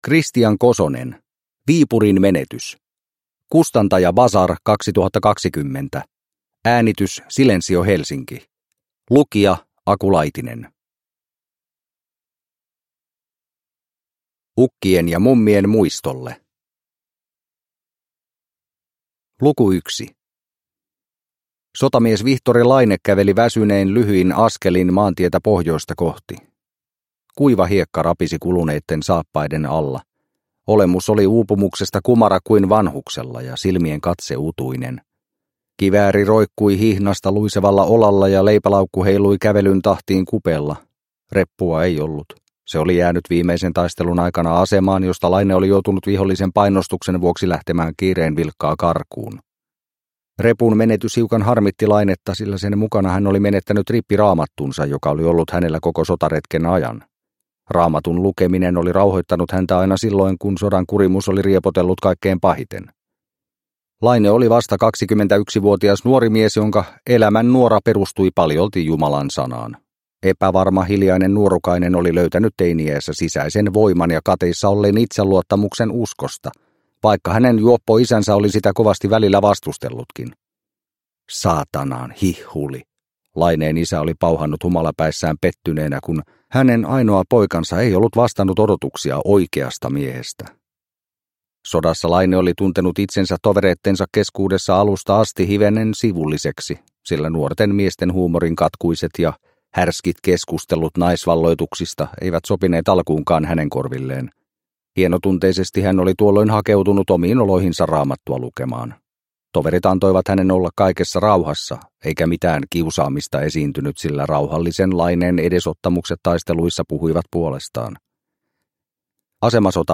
Viipurin menetys – Ljudbok – Laddas ner